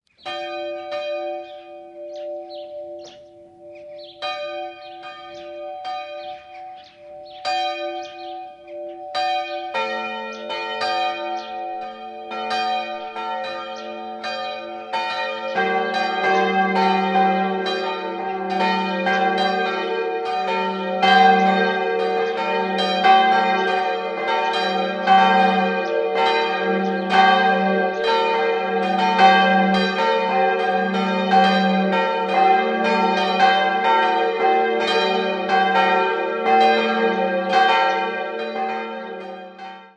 3-stimmiges TeDeum-Geläute: g'-b'-es'' Die kleine Glocke dürfte historisch sein, die beiden anderen wurden 1949 von Karl Czudnochowsky in Erding in Euphonlegierung gegossen. Sie erklingen in den Tönen g’-4 und b’-7.